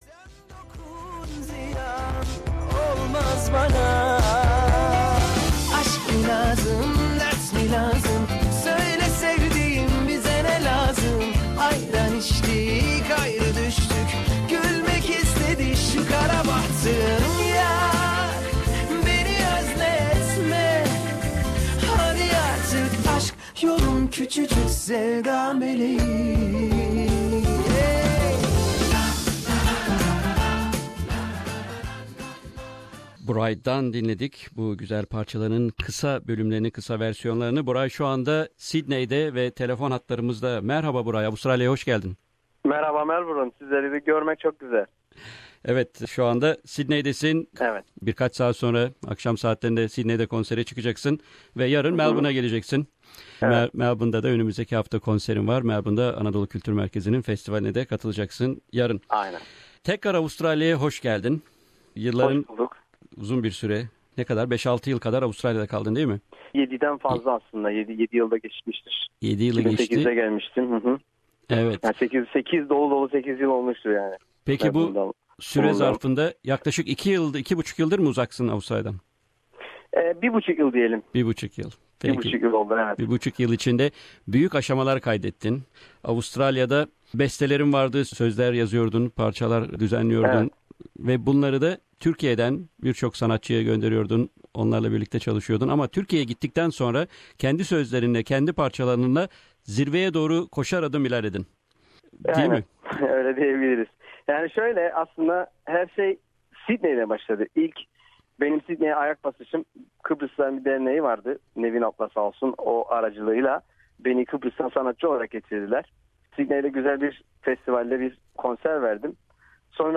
SBS Turkish had an interview with Buray on its live program.